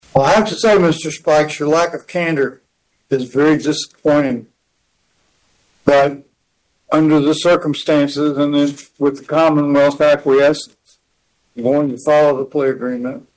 Atkins also expressed his dismay in this part of the trial’s proceedings.